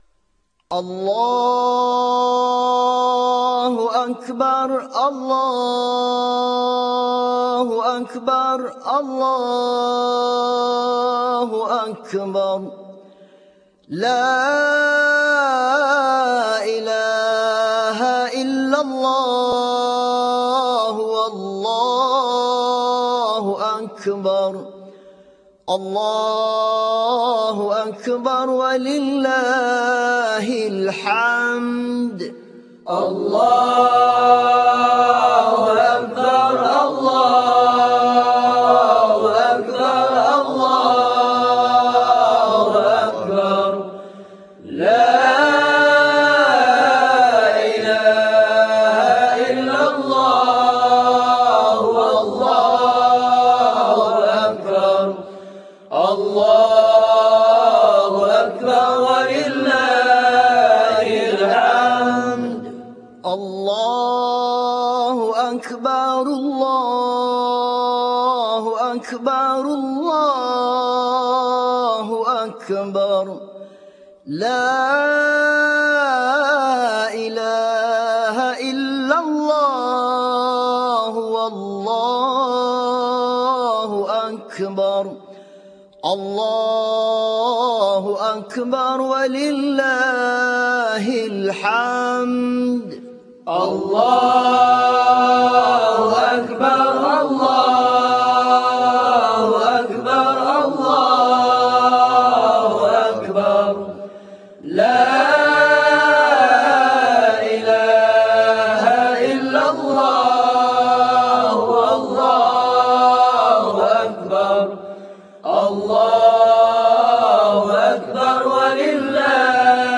TakbirHariRaya2.ram